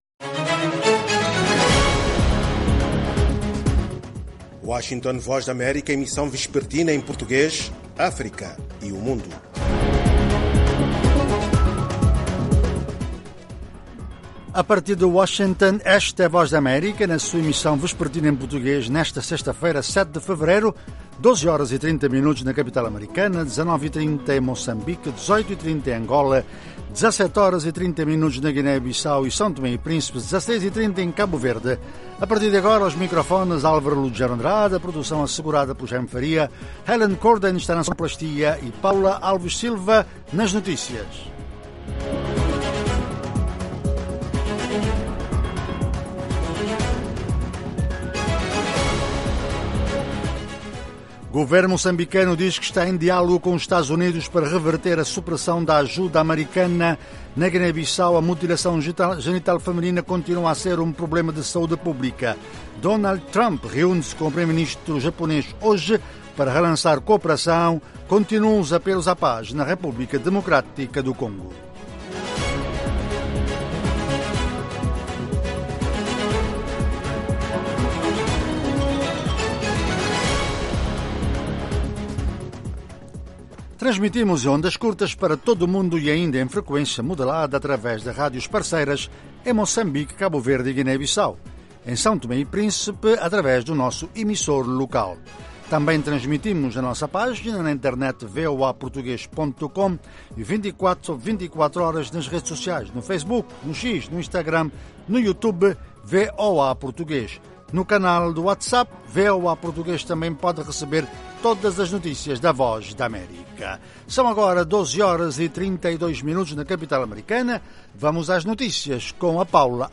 Oferece noticias do dia, informação, analises, desporto, artes, entretenimento, saúde, questões em debate em África. Às sextas em especial um convidado explora vários ângulos de um tema.